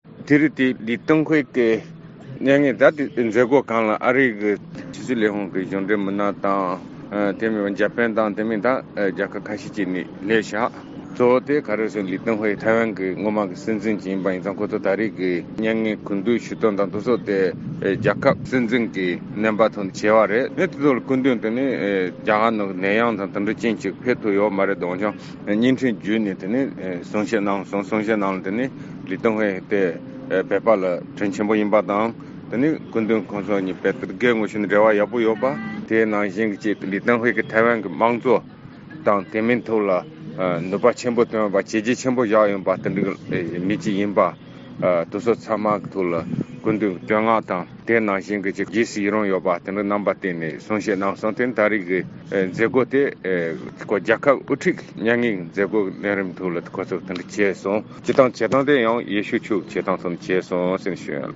ཐེ་ཝན་བོད་ཀྱི་སྐུ་ཚབ་དོན་གཅོད་ཟླ་བ་ཚེ་རིང་ལགས་ཀྱིས་འདི་ག་རླུང་འཕྲིན་ཁང་མཛད་སྒོ་དེའི་སྐོར་གསུངས་སྐབས།
སྒྲ་ལྡན་གསར་འགྱུར། སྒྲ་ཕབ་ལེན།